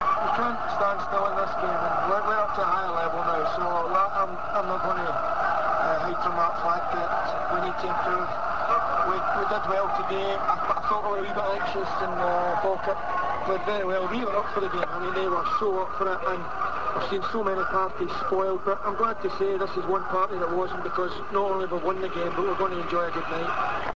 Alex McLeish on Radio Scotland after the game (50k)
alex_mcleish_radio.rm